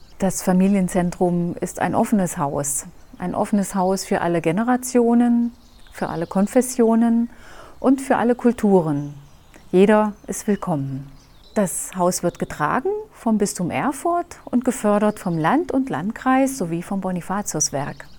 O-Töne und Moderationen zu Ihrer redaktionellen Verwendung
O-TÖNE AUS EINEM DER BEISPIELPROJEKTE: FAMILIENZENTRUM „KERBSCHER BERG“ IN DINGELSTÄDT